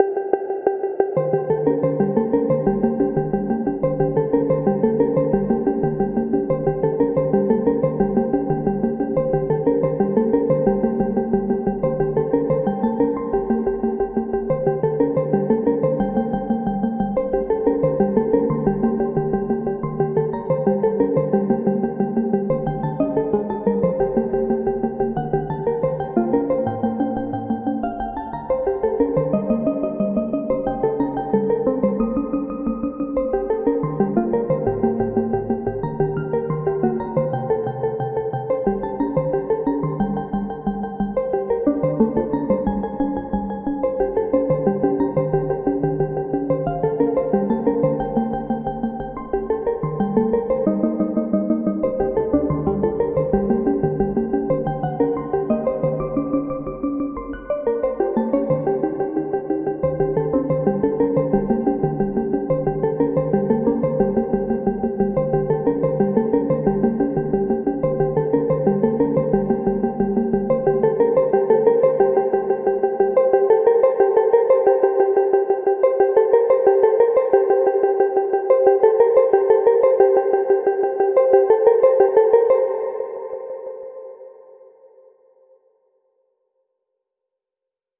Pieza de electrónica experimental
Música electrónica
percusión
repetitivo
rítmico
sintetizador